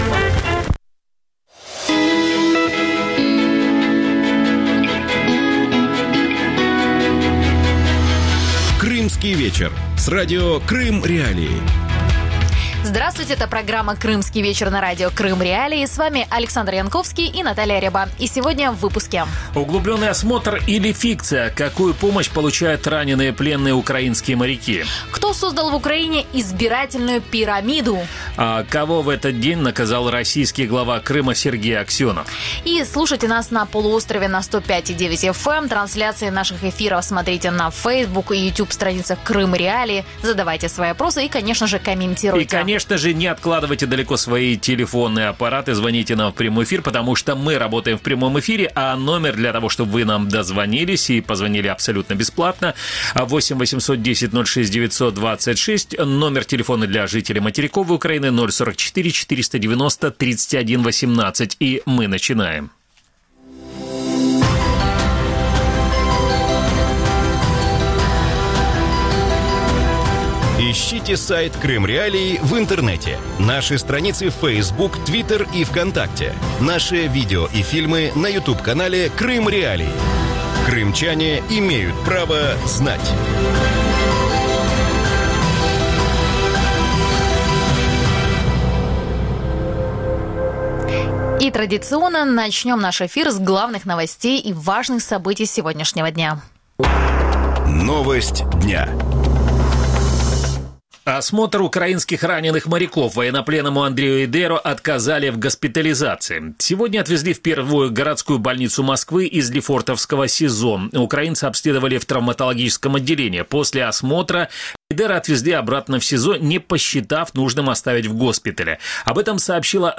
Гость студии: украинский военачальник, экс-командующий Военно-морскими силами Украины Сергей Гайдук.